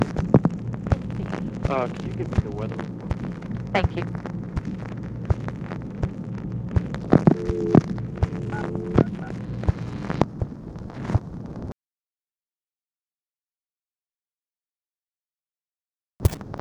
UNIDENTIFIED MALE ASKS FOR WEATHER REPORT
Conversation with UNKNOWN
Secret White House Tapes